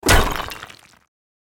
拍墙、地板音效.MP3